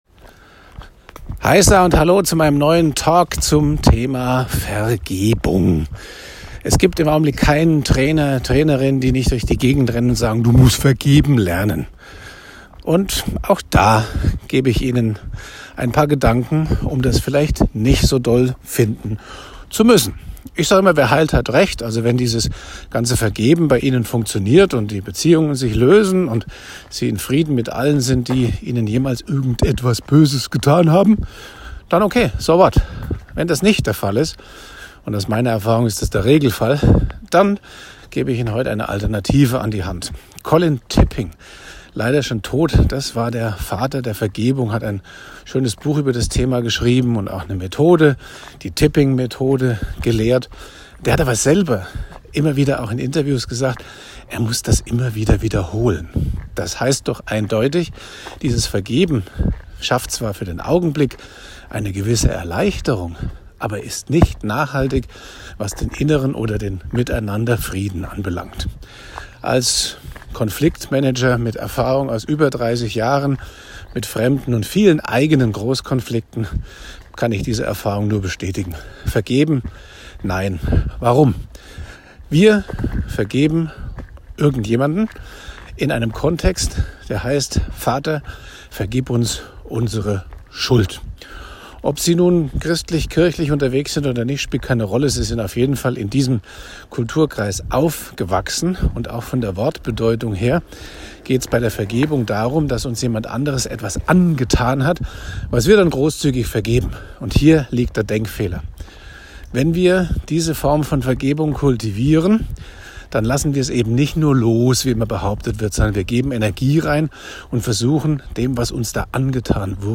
Hier geht es zu meinem Schwerpunkt-Talk dazu mit einer überraschend wirksamen Alternative.